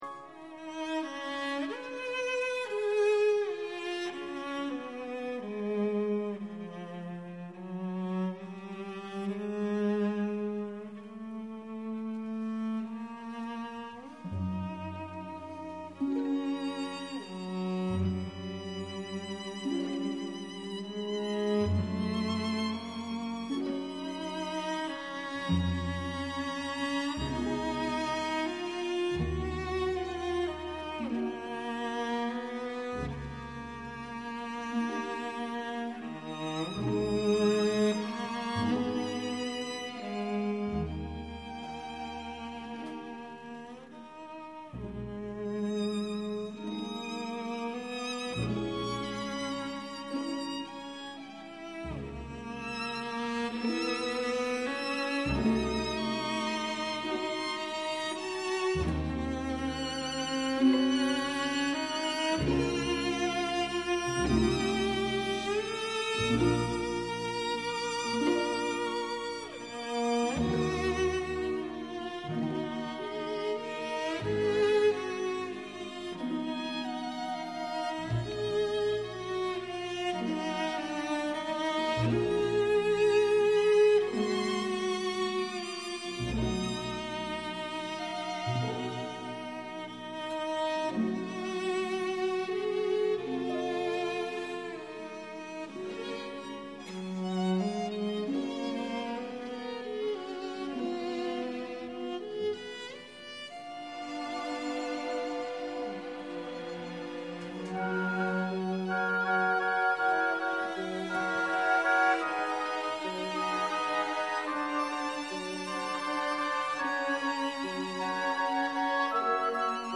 De muziek bij de voorstelling van Het Zwanenmeer wordt gespeeld door Het Balletorkest.